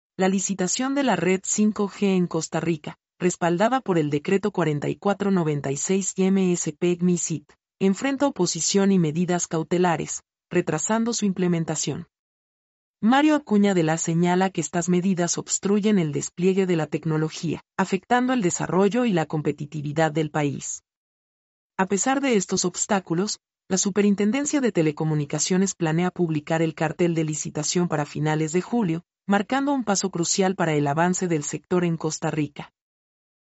mp3-output-ttsfreedotcom-19-1-1.mp3